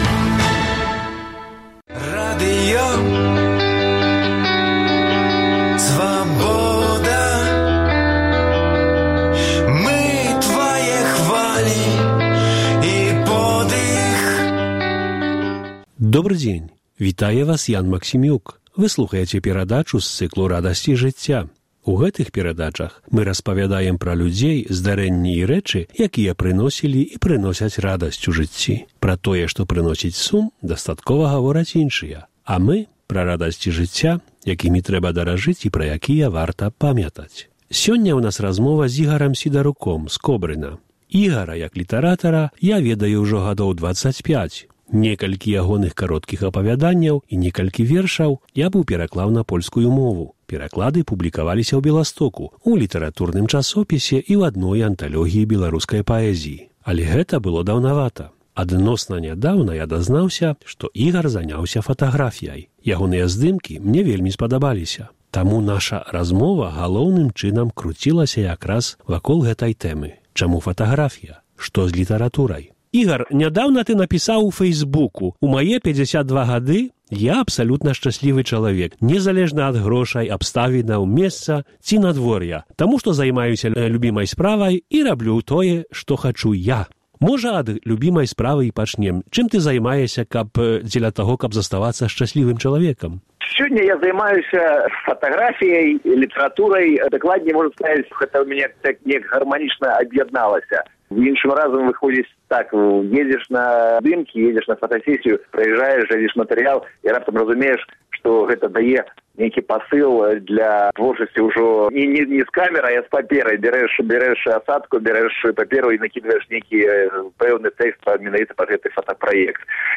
Размова